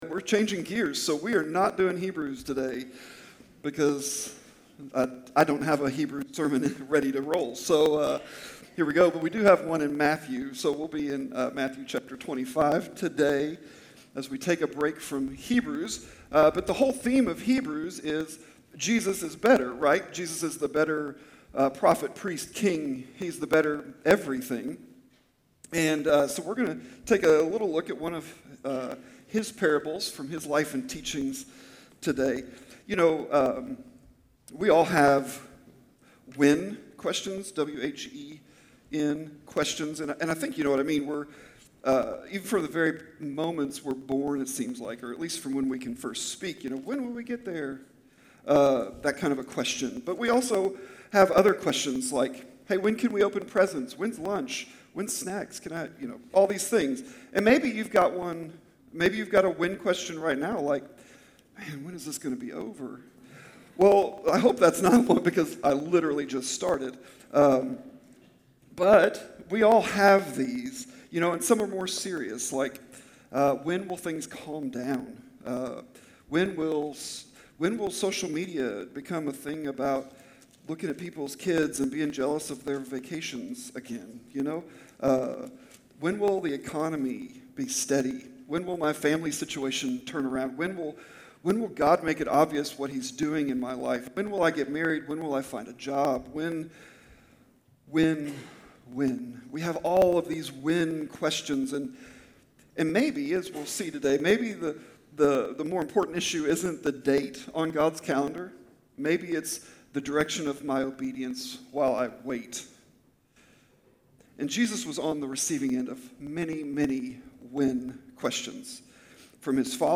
Sermons | Campbellsville Christian Church